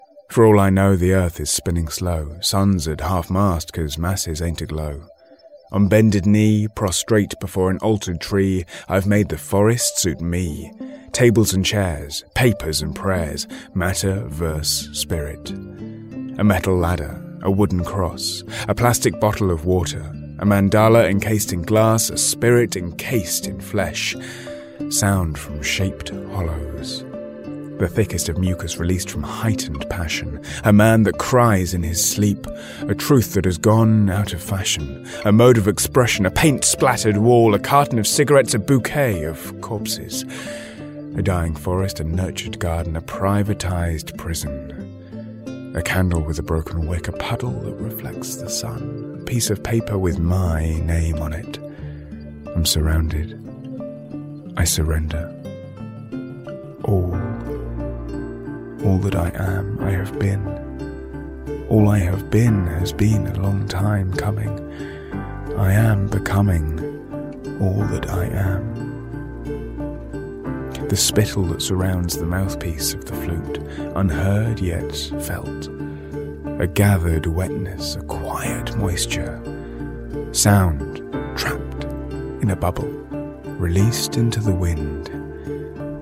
English (British)
Custom-built home studio